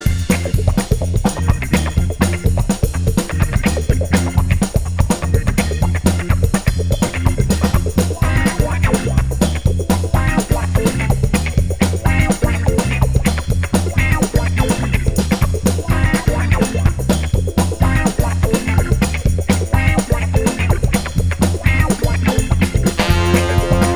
Live Pop (1960s) 1:58 Buy £1.50